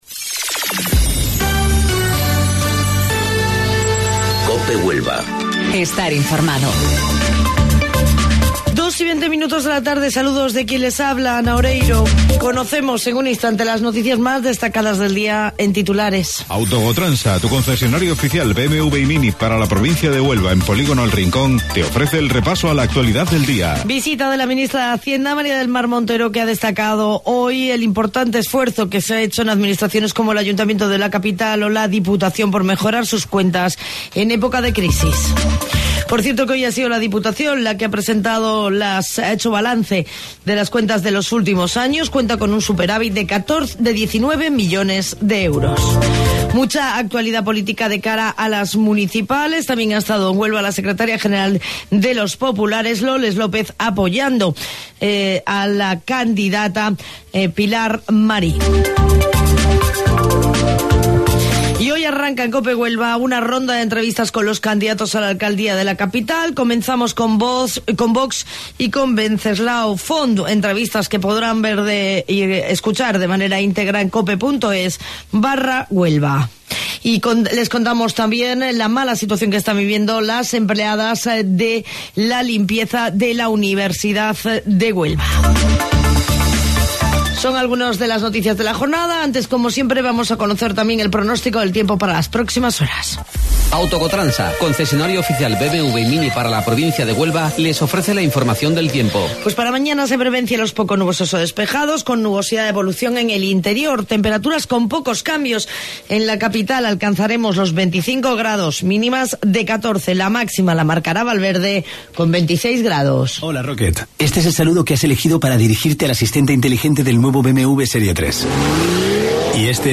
AUDIO: Informativo Local 14:20 del 20 de Mayo